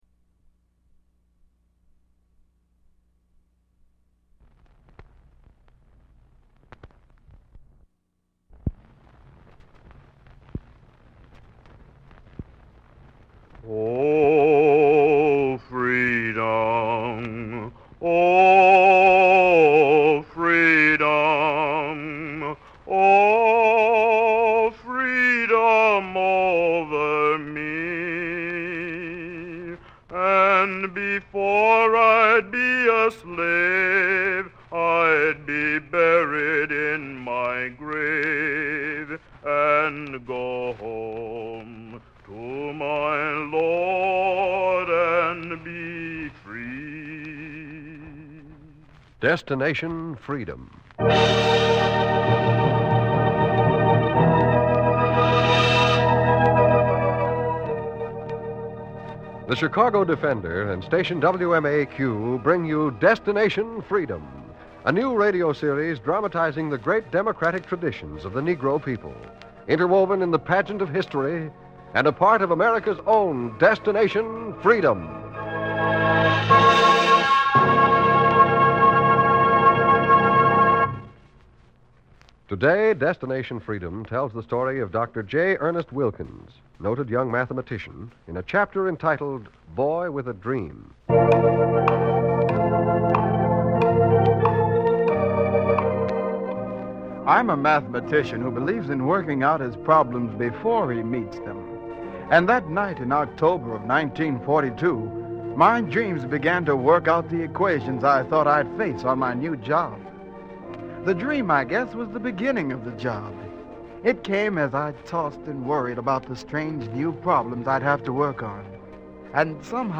Through powerful dramatizations, the series illuminated the lives and legacies of notable African Americans, offering stories of resilience, innovation, and triumph that were seldom highlighted in mainstream media.